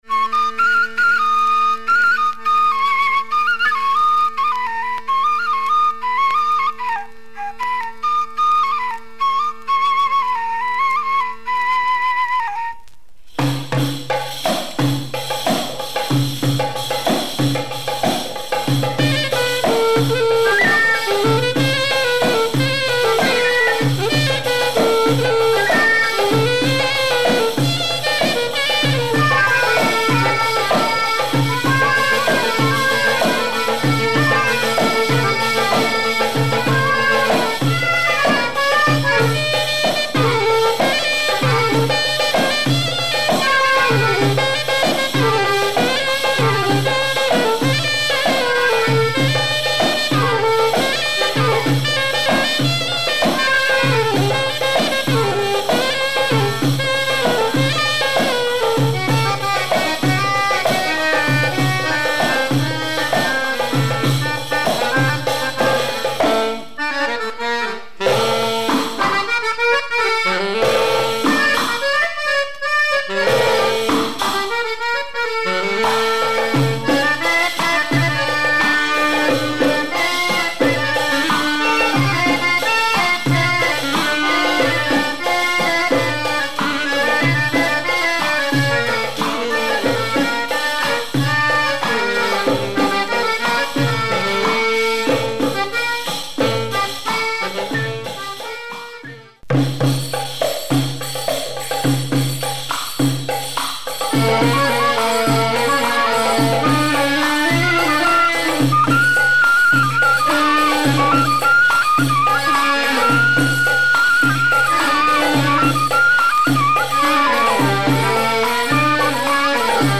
Arabic & Persian